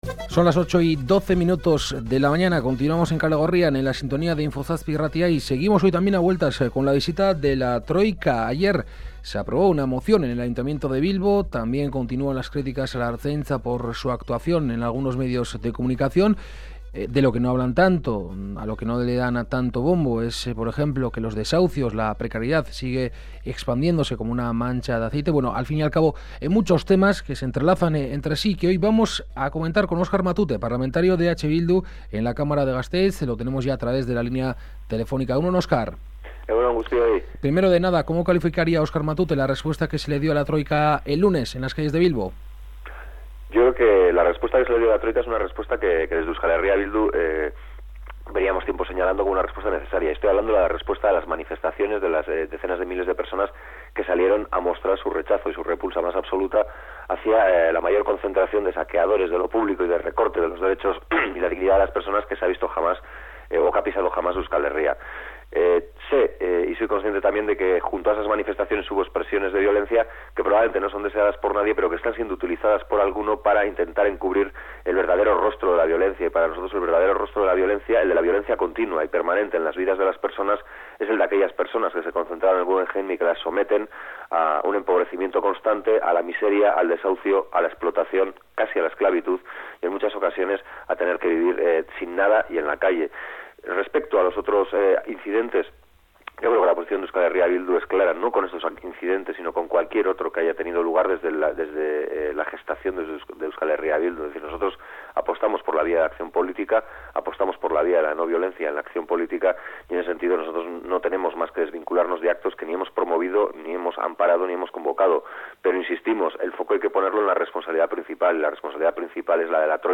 Esta mañana en Kalegorrian hemos hablado con Oscar Matute que ha opinado, entre otras cuestiones, sobre la Cumbre de la Troika y en torno a las movilizaciones organizadas por la plataforma Gune.